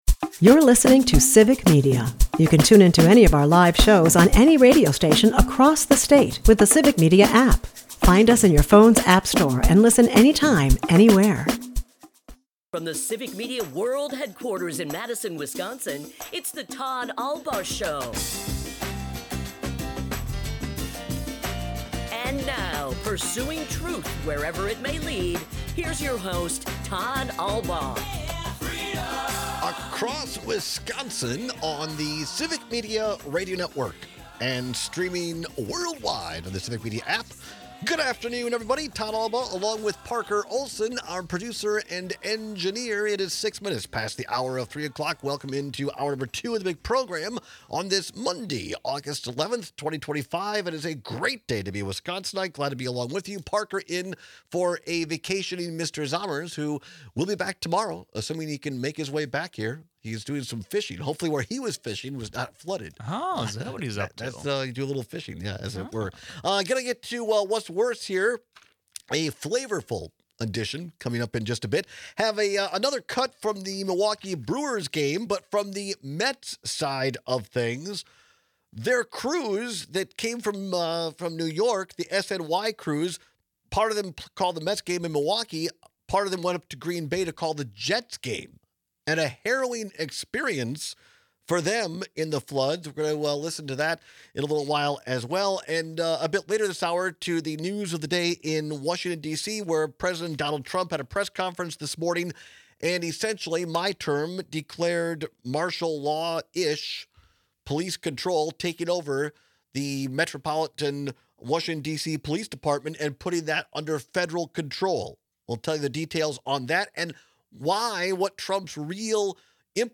Callers join and raise questions of martial law.